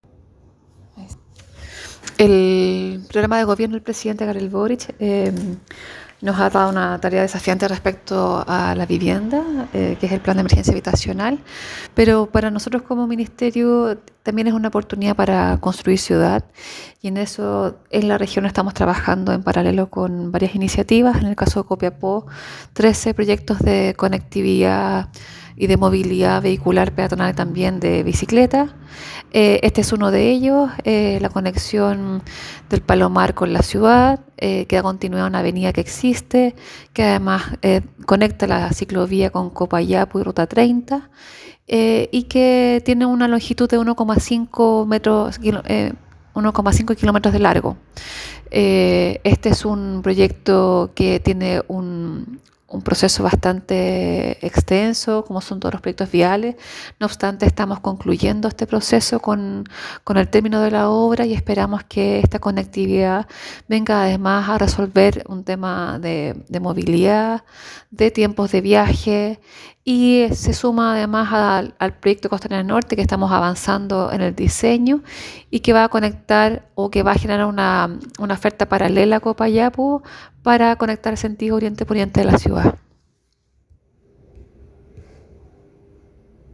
Entrevista a Seremi Minvu